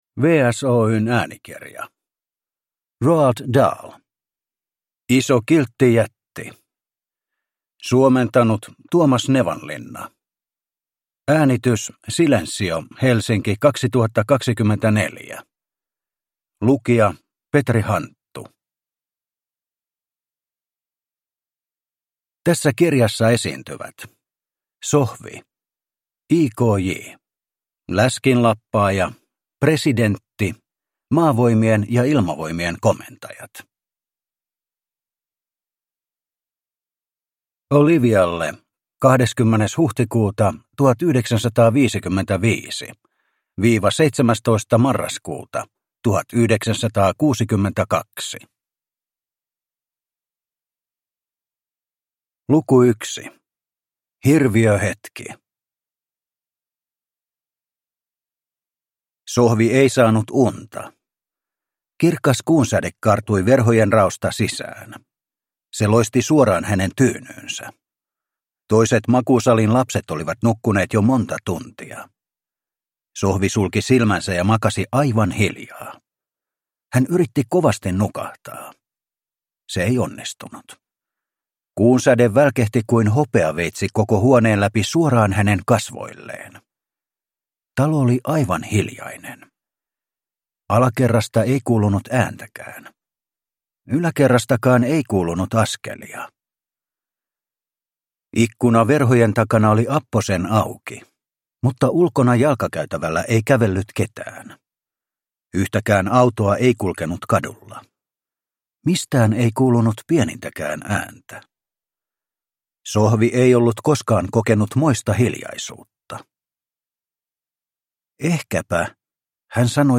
Iso Kiltti Jätti – Ljudbok